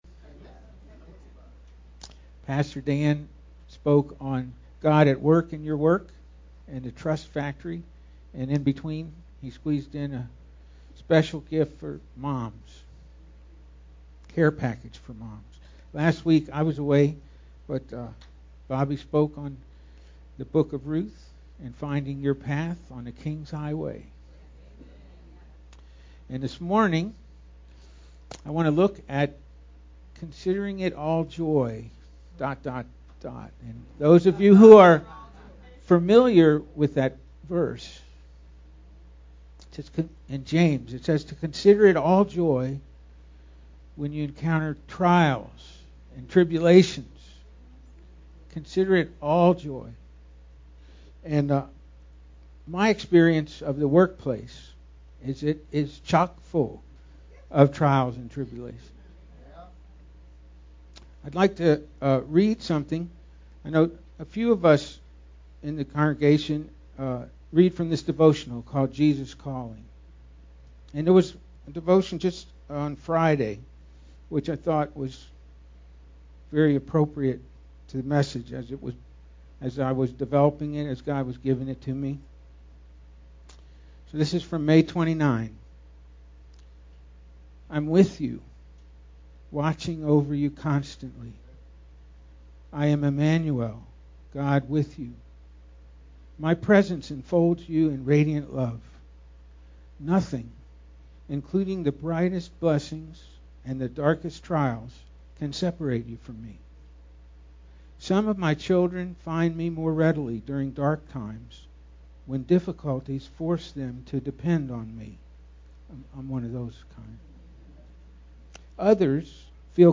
Sermon of 5/31/15